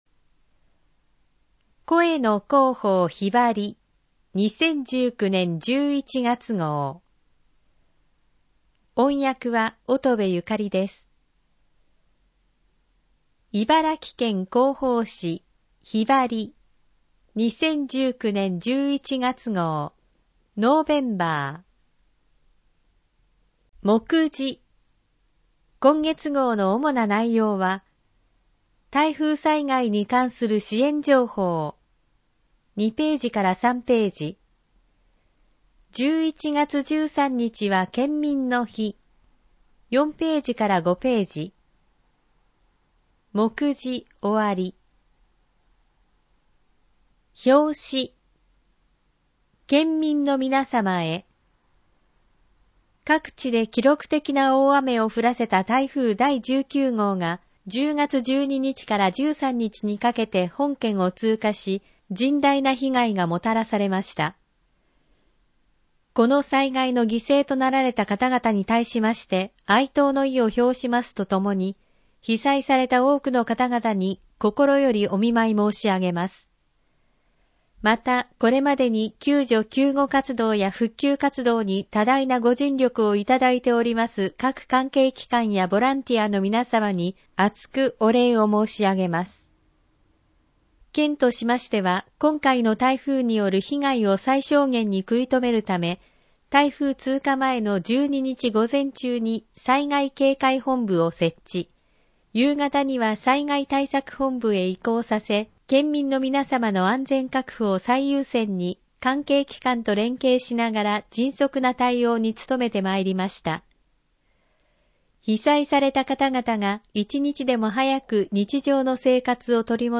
音声版・点字版 視覚障害の方を対象に音声版 も発行しています。